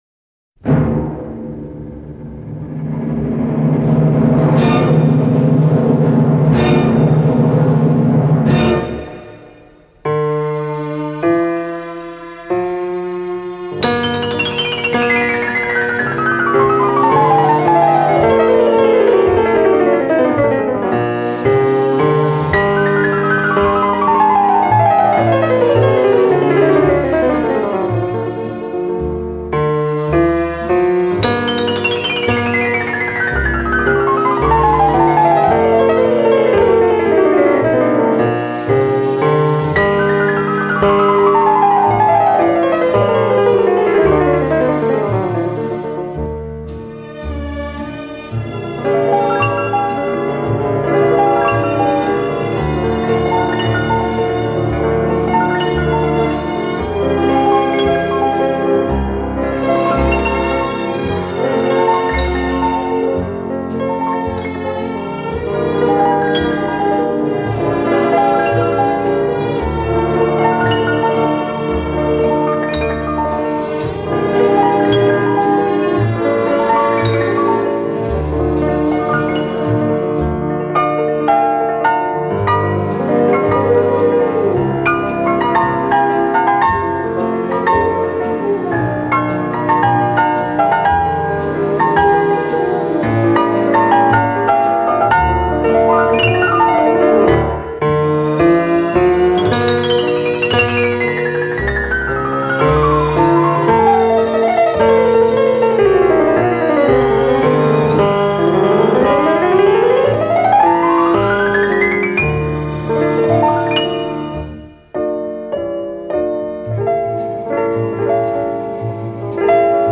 これは当時、 インストゥルメンタル の、しかも外国曲としては極めて異例の大ヒットだった。